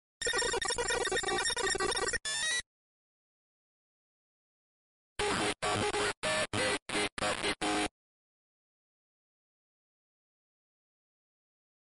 running on a Commodore PET 4032 from 1980